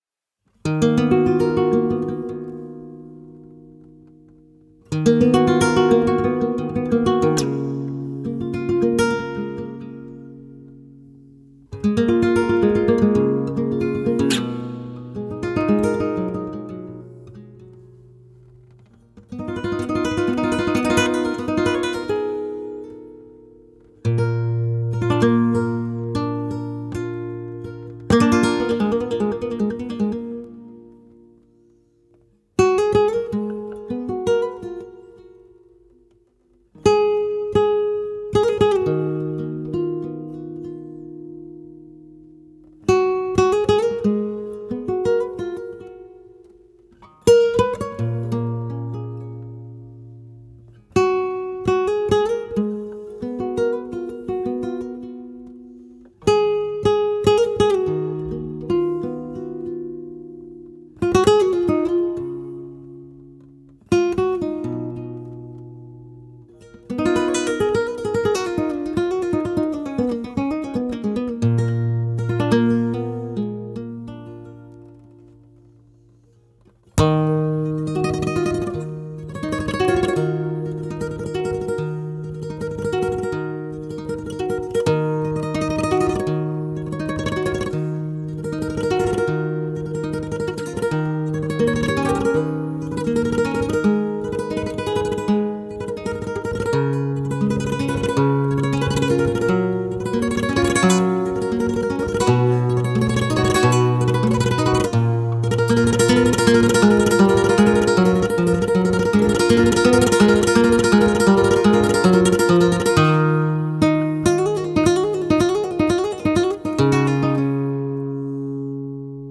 Zurück zu: Flamenco
Jaleos 3:40